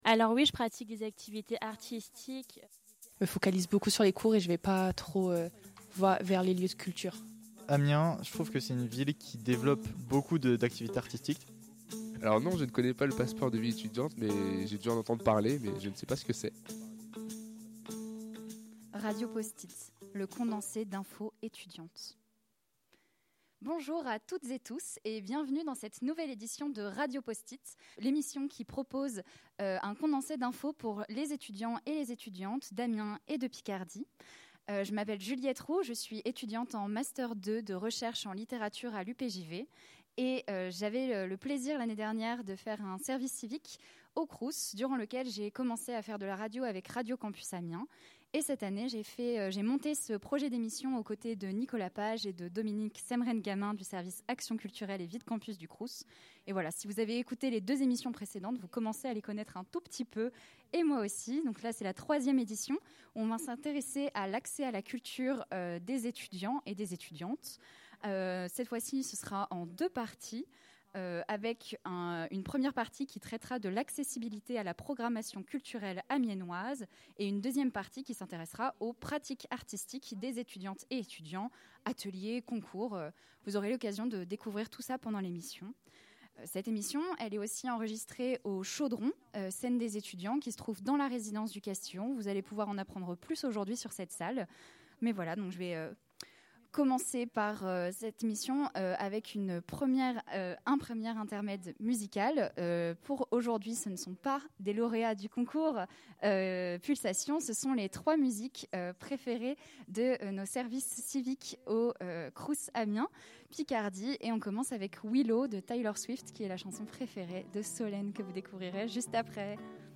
Radio Post-it est une série de cinq émissions qui propose à chaque fois un condensé d’informations autour de thèmes centraux de la vie étudiante et ce sous forme de table ronde.